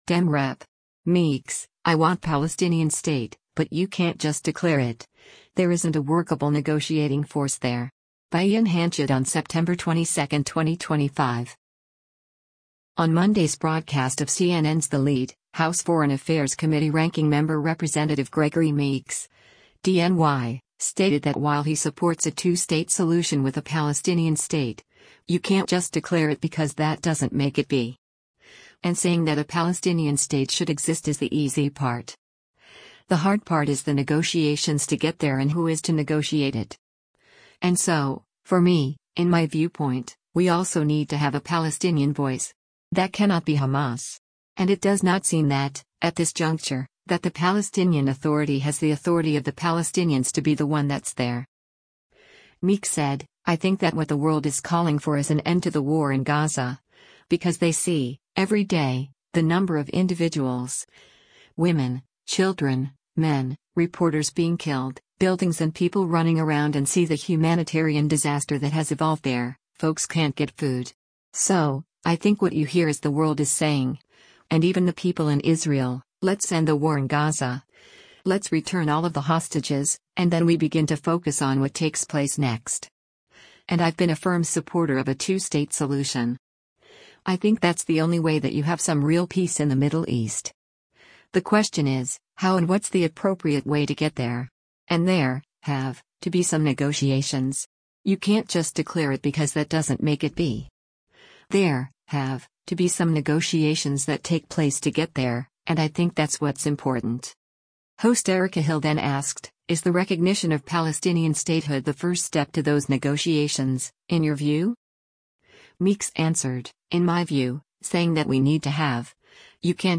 On Monday’s broadcast of CNN’s “The Lead,” House Foreign Affairs Committee Ranking Member Rep. Gregory Meeks (D-NY) stated that while he supports a two-state solution with a Palestinian state, “You can’t just declare it because that doesn’t make it be.”
Host Erica Hill then asked, “Is the recognition of Palestinian statehood the first step to those negotiations, in your view?”